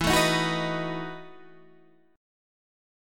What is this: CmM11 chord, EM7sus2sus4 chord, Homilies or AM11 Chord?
EM7sus2sus4 chord